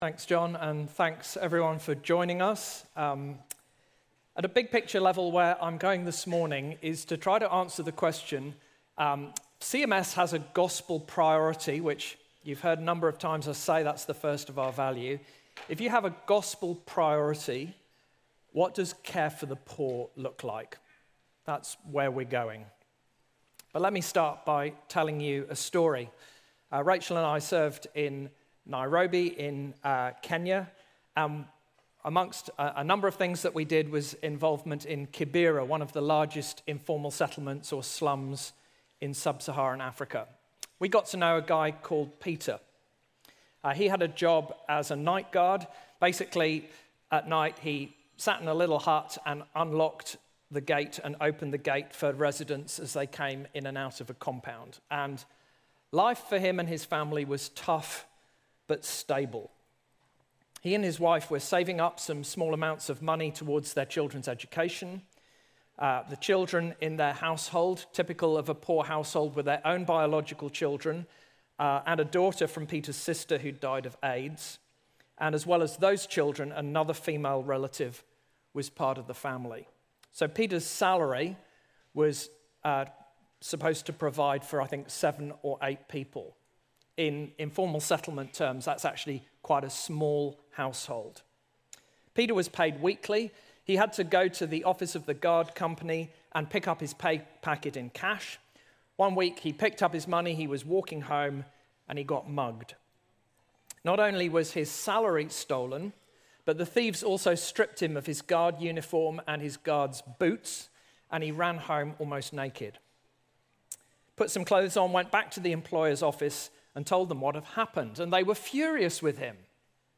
Audio Talk